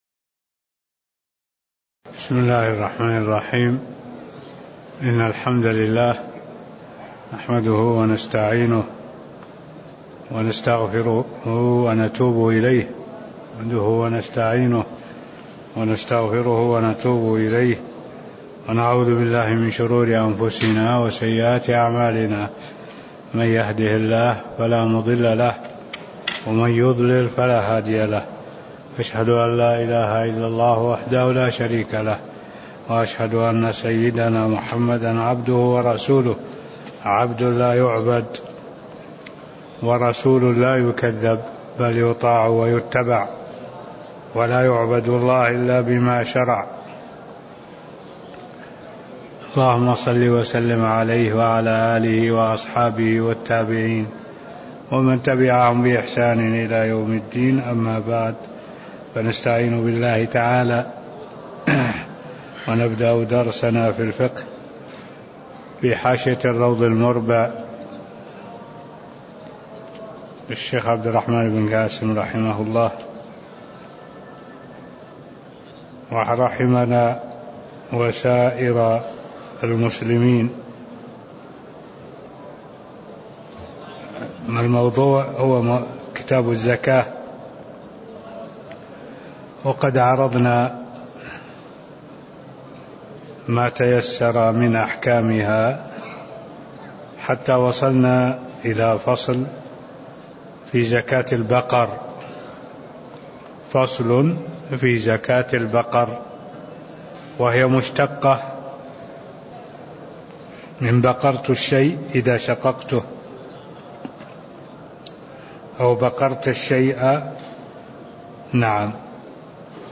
تاريخ النشر ٢٤ محرم ١٤٢٧ هـ المكان: المسجد النبوي الشيخ